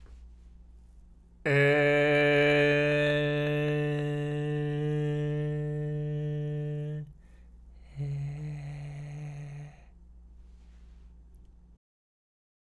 ステップ2：舌先を前歯で甘噛みした状態で、ステップ1の工程を行いパーの声「え（へ）」と出す。